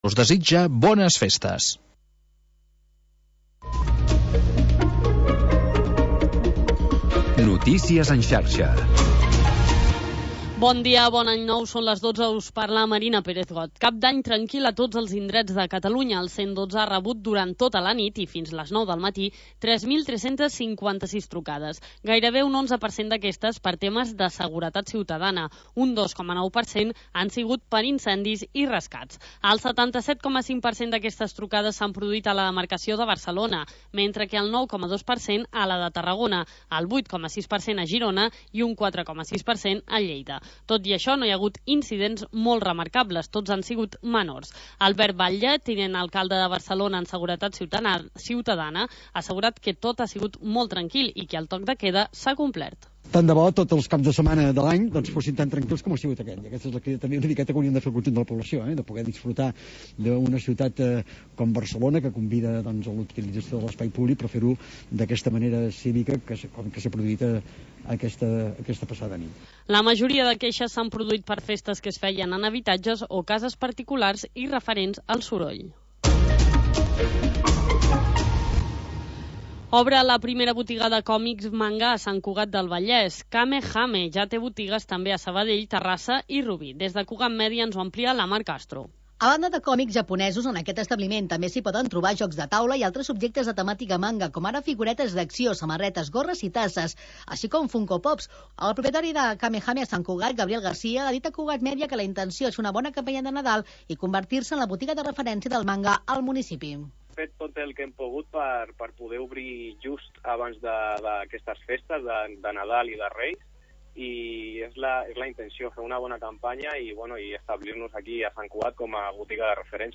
Programa sardanista